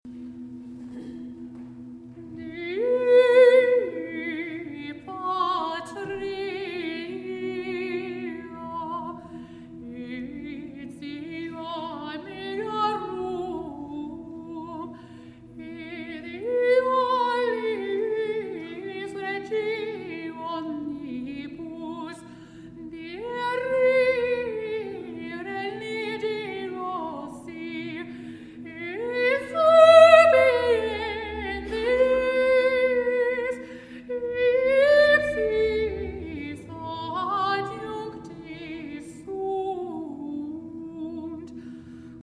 Grabaciones del concierto en directo
Gregorian style chant with eastern meditation bowls